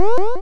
bfxr_teleportlow.wav